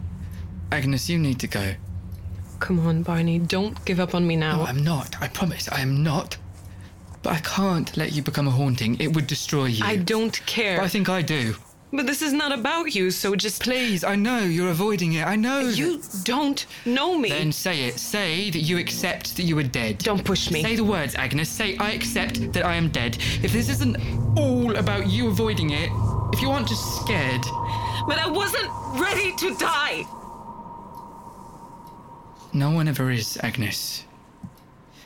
20 - 40 ans - Contralto